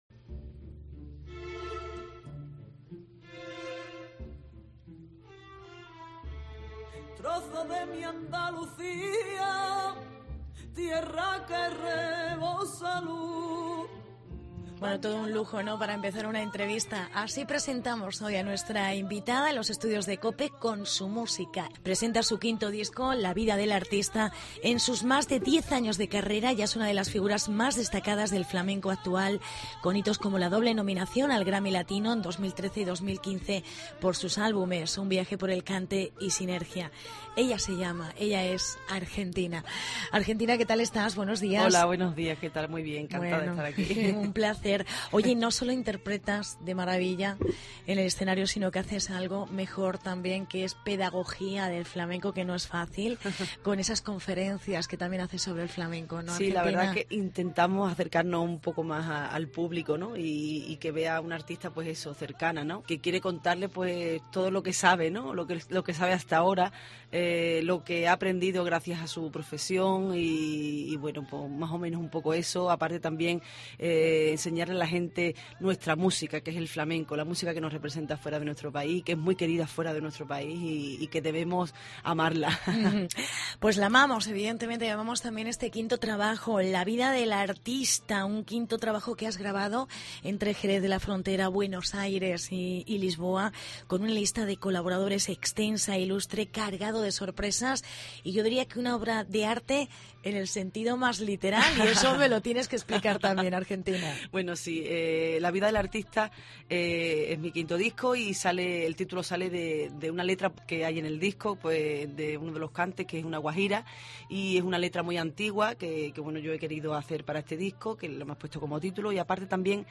La entrevistamos en los estudios Cope Toledo